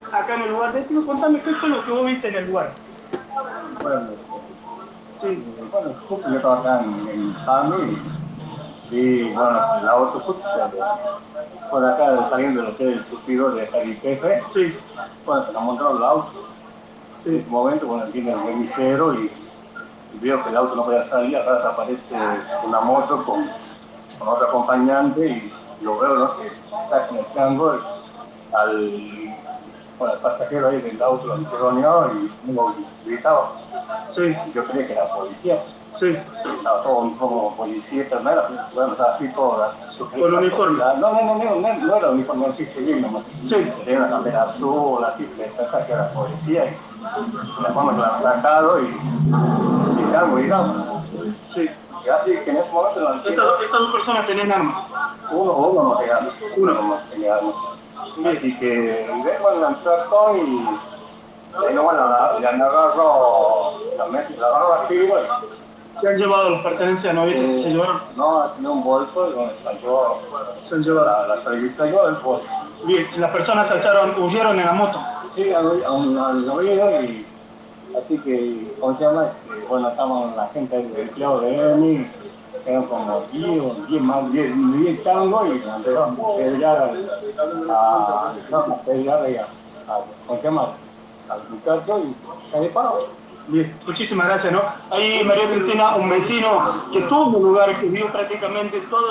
ENTREVISTA A UN TESTIGO EN EL LUGAR